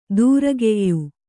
♪ dūrageyyu